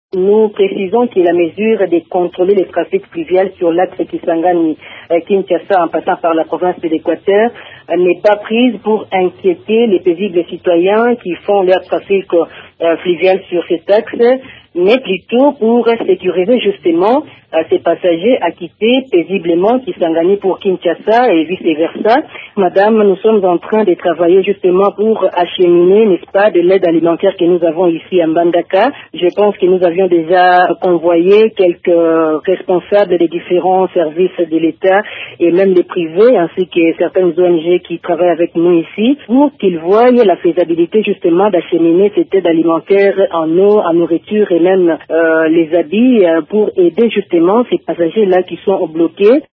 La ministre provinciale de Communication de Équateur, Rebecca Ebale, explique le bien fondé de cette mesure et de la prise en charge sur le plan humanitaire: